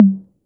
TR 808 Tom 03.wav